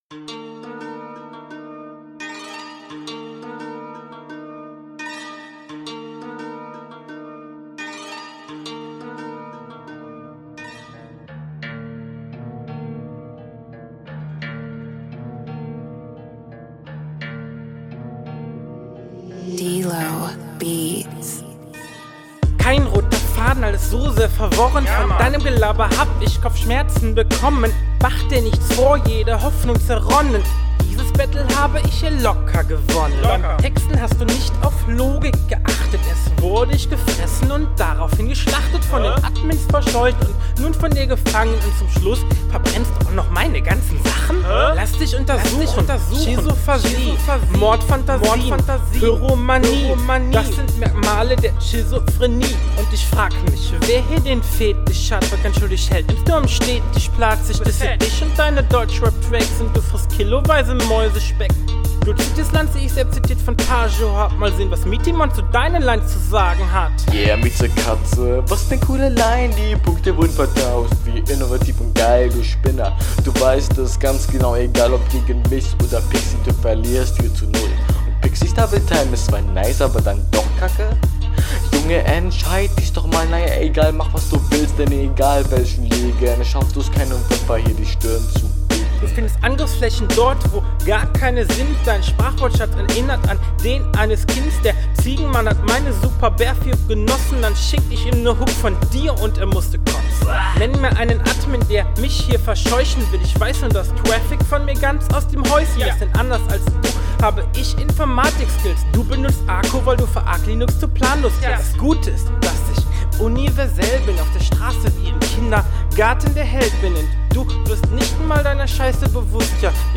TRIFFST DEN TAKT UND KLINGT DOPEText: Bist dein Gegner überlegen Soundqualität: gut …
Flow: flow ist auch überhaupt nicht gut und extremst oft offbeat Text: konter sind nicht …